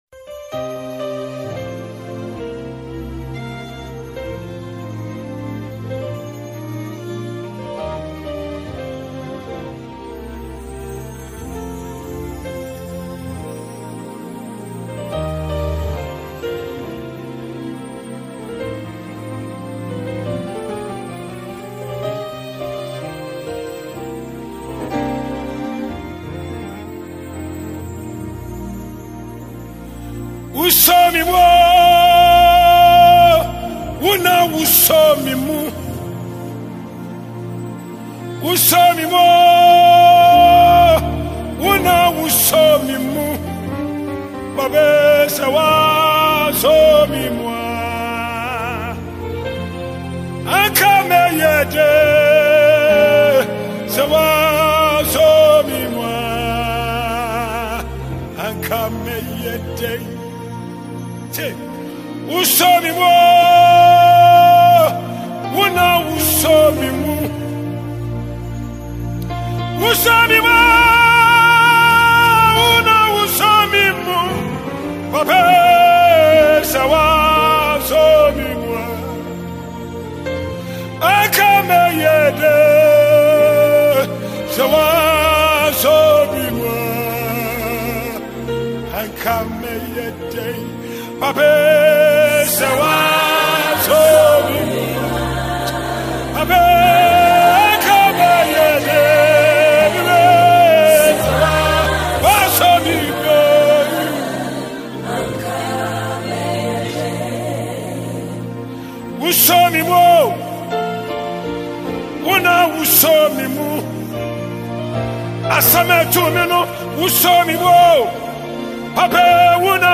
gospel inspirational singer
gospel song